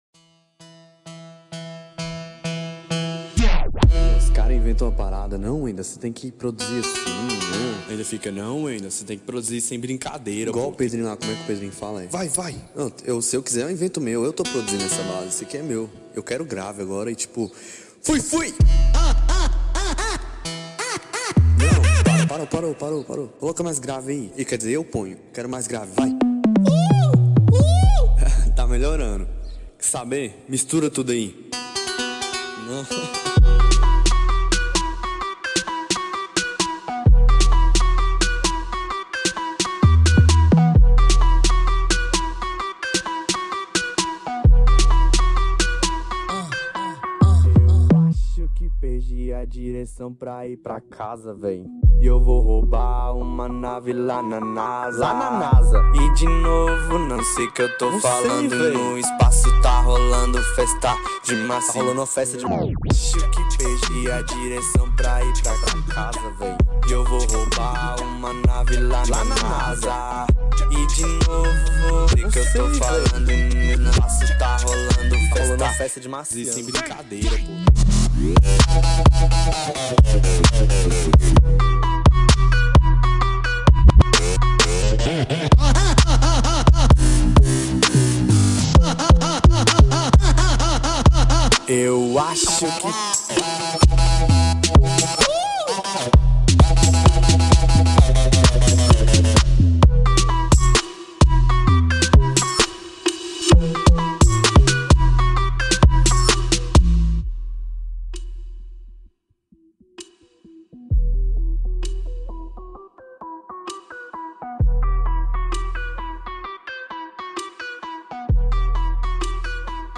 Trap.